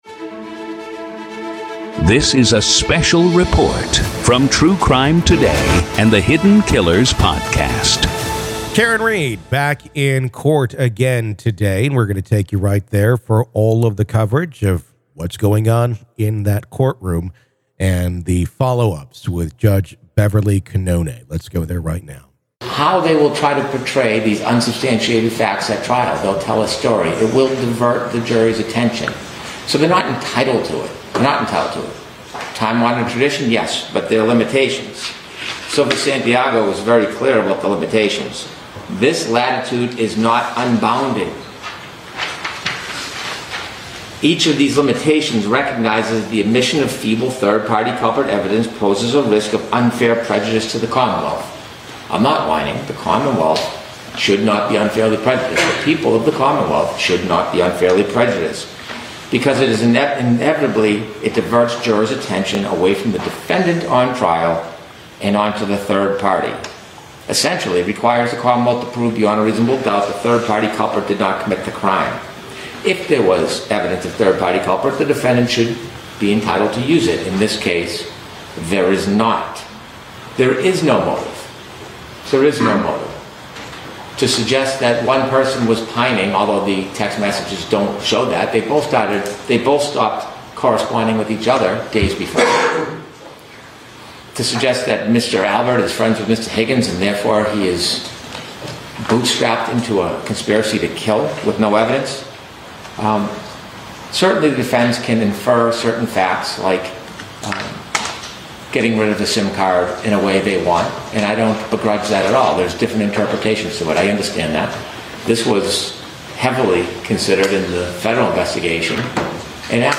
COURT AUDIO: MA v. Karen Read Murder Retrial - Motions Hearing Day 3 PART 1